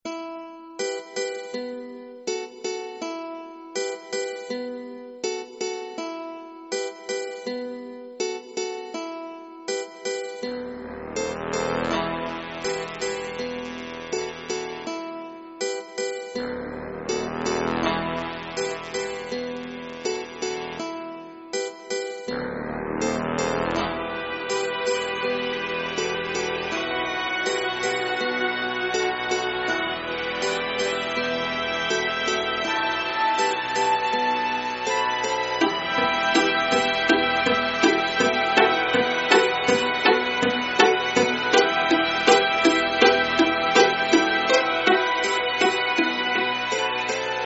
Estilo: Urbano
Pista musical para jingles